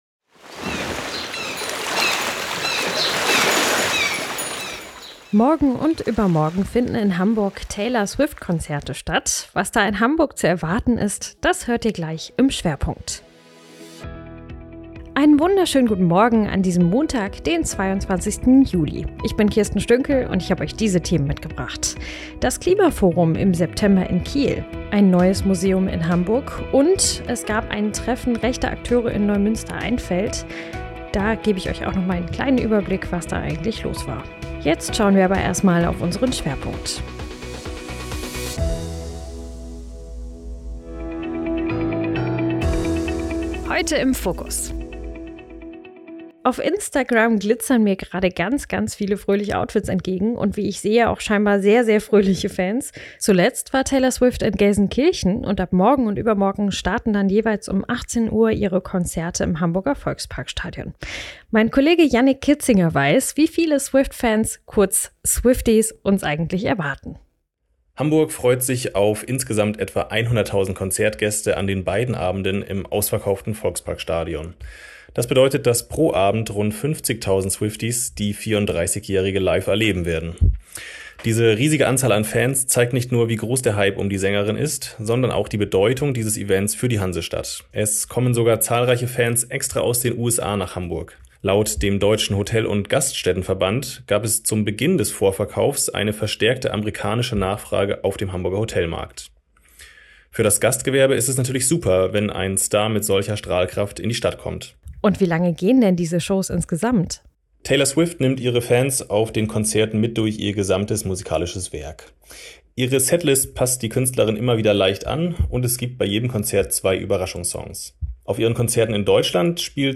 Guten Morgen SH — Dein News-Podcast für Schleswig-Holstein
Nachrichten